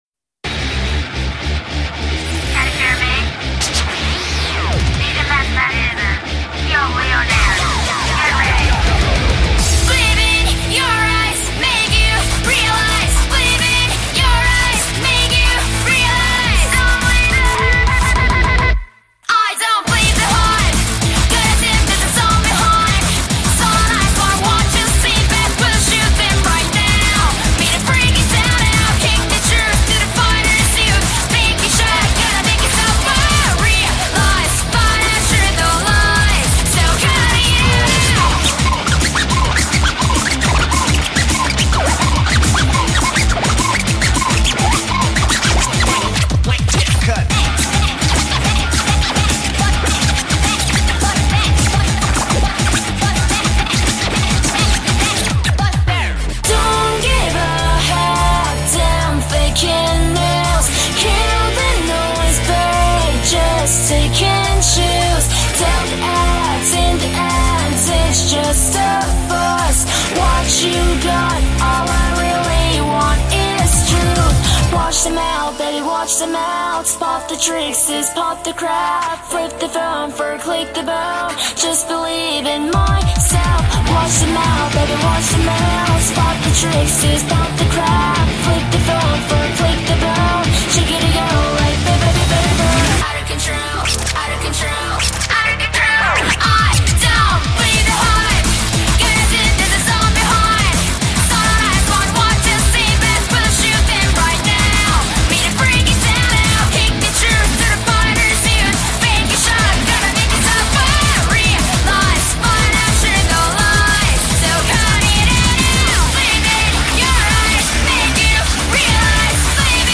BPM210
Audio QualityPerfect (Low Quality)